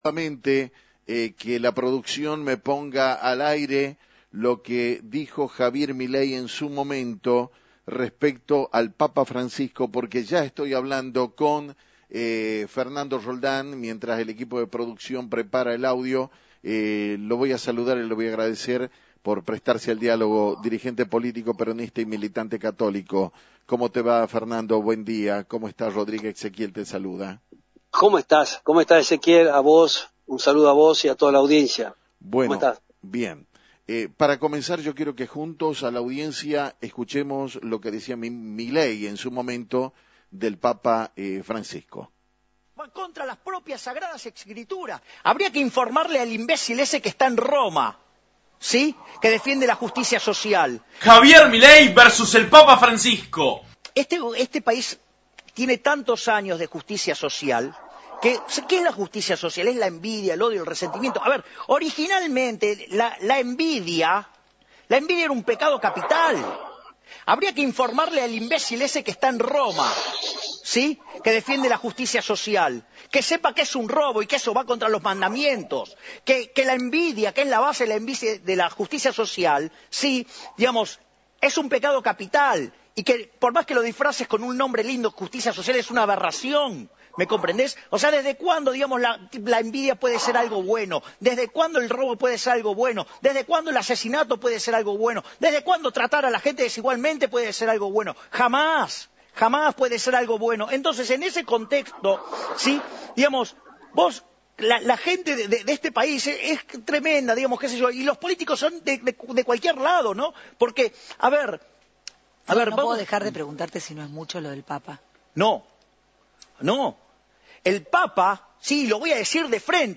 En dialogo con Actualidad en Metro